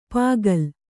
♪ pāgal